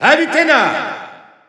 Announcer pronouncing Palutena.
Palutena_French_Announcer_SSBU.wav